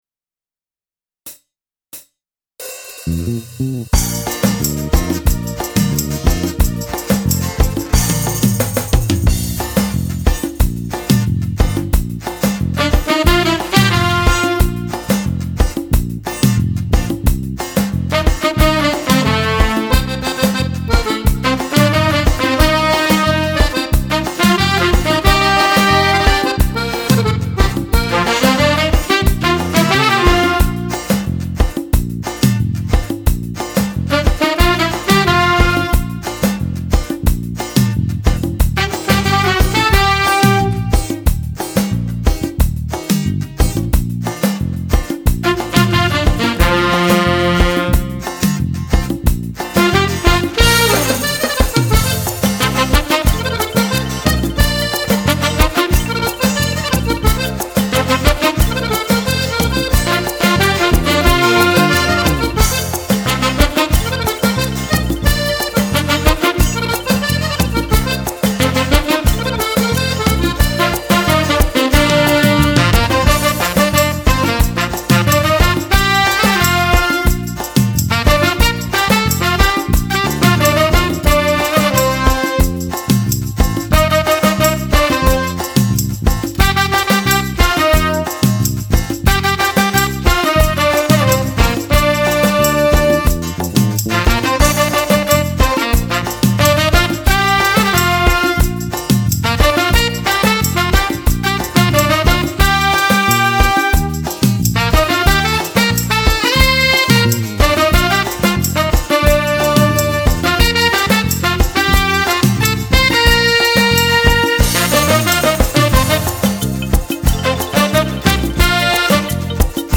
Bajon
Fisarmonica